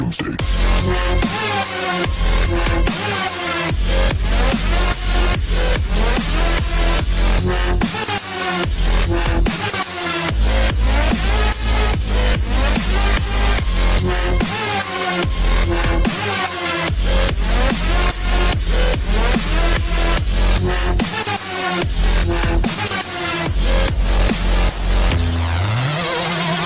• Качество: 128, Stereo